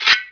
shield_wear.WAV